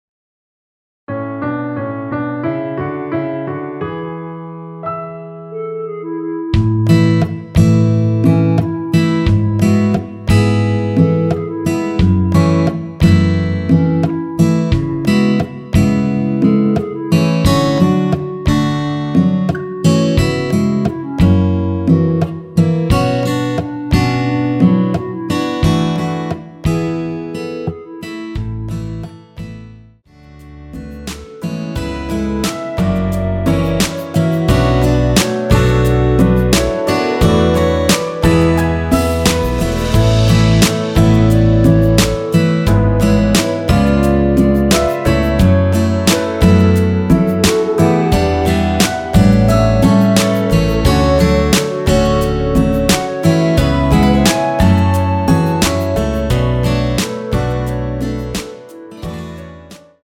원키 멜로디 포함된 MR이며 여자파트 멜로디는 없습니다.(미리듣기 참조)
앞부분30초, 뒷부분30초씩 편집해서 올려 드리고 있습니다.
중간에 음이 끈어지고 다시 나오는 이유는